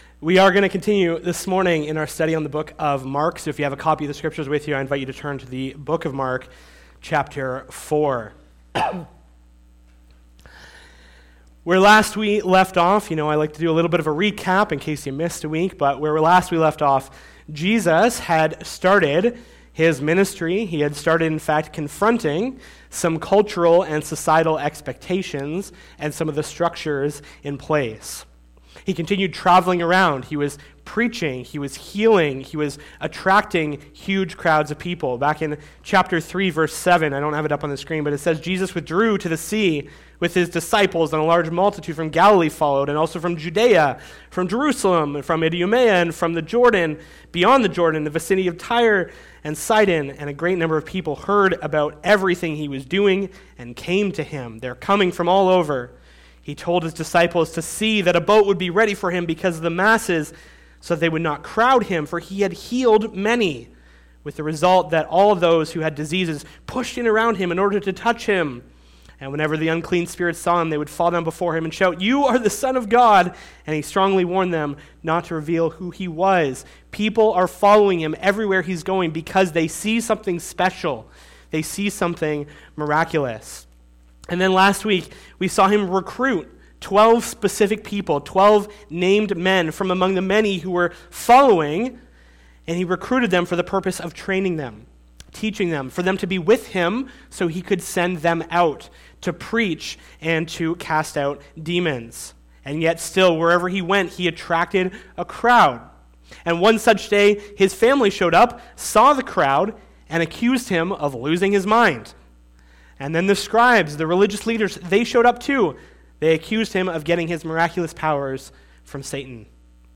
SERMONS - Community Bible Church